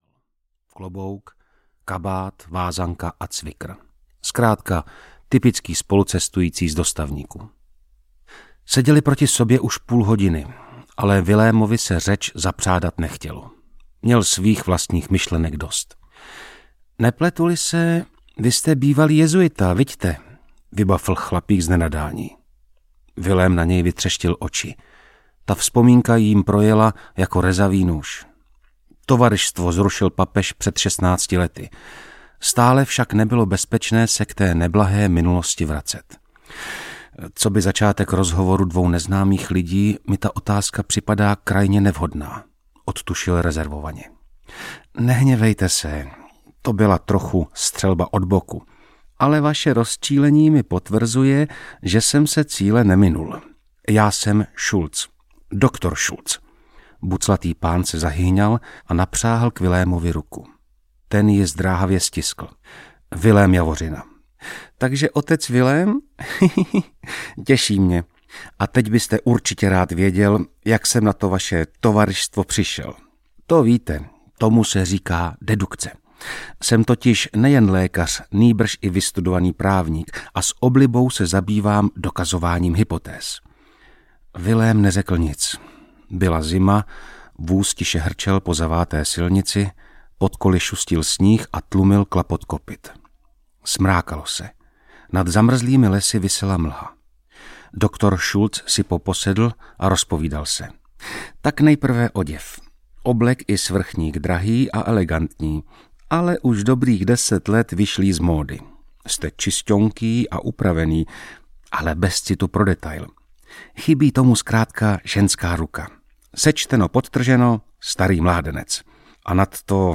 Čarostřelec audiokniha
Ukázka z knihy
• InterpretMartin Pechlát
carostrelec-audiokniha